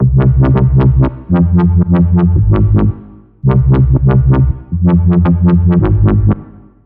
描述：机器人DubStep BAZZ 01
Tag: 140 bpm Dubstep Loops Bass Loops 1.15 MB wav Key : Unknown